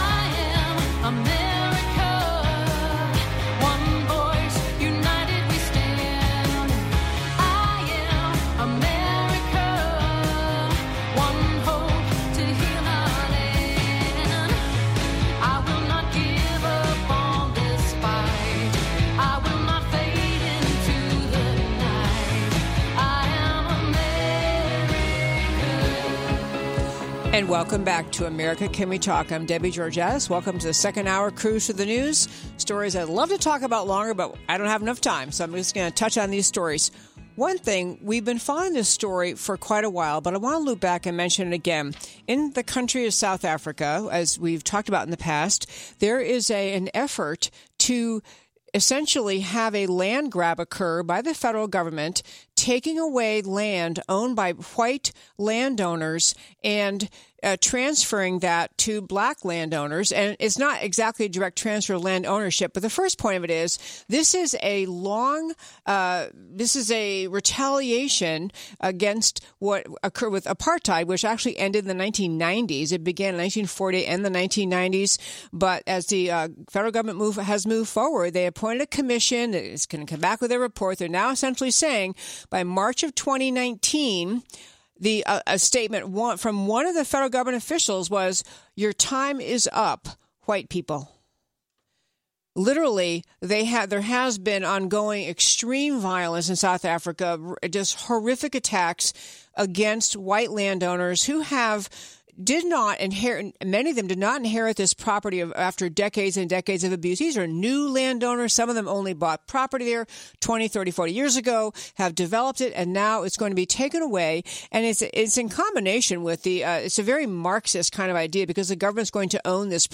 Guest in studio interview – Frank Gaffney, Center for Security Policy